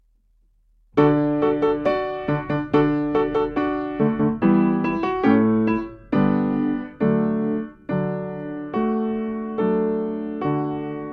I część: 60 BMP  i 70 BMP
Nagrania dokonane na pianinie Yamaha P2, strój 440Hz
piano